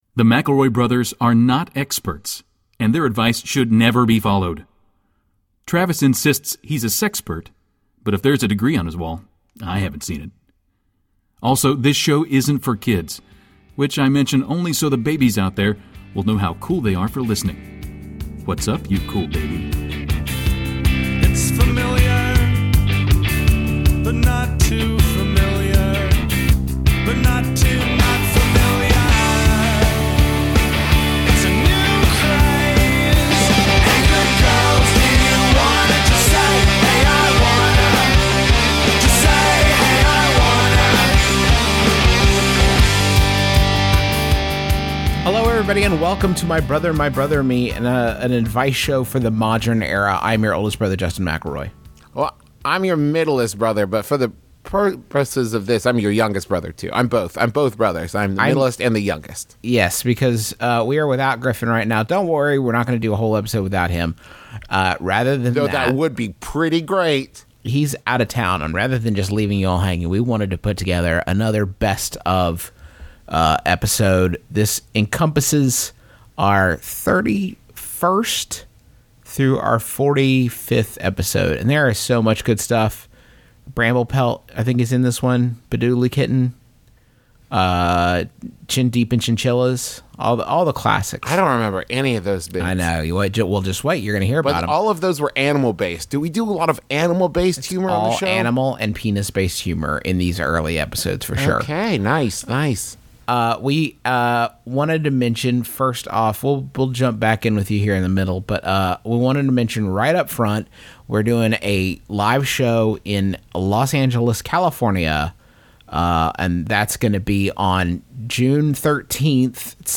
Mbmbam, Mcelroy Brothers, Advice, Justin Mcelroy, Travis Mcelroy, Comedy Advice, Mcelroy, Griffin Mcelroy, Comedy